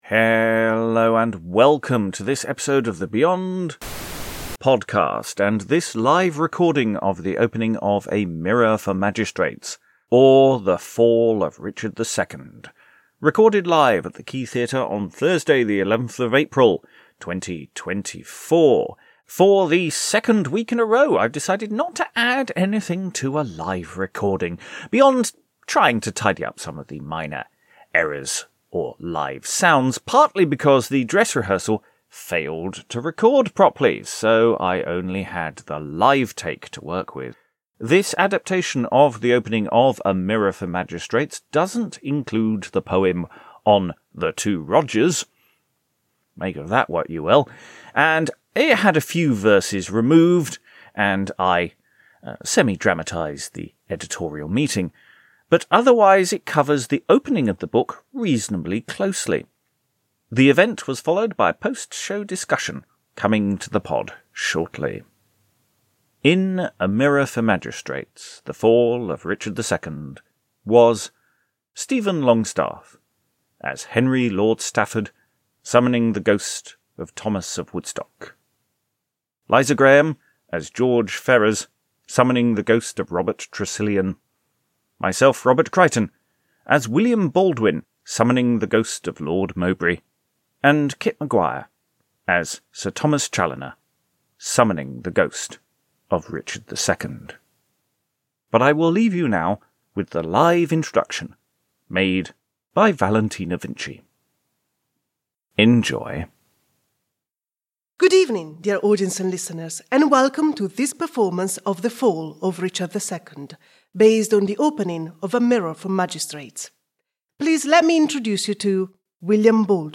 Live recording of our adaptation of the opening of A Mirror for Magistrates
We performed it live, and there were a few issues with the recording, so I've kept the edit fairly simple.
Performed on Thursday 11th April 2024 at the Quay Theatre, Sudbury.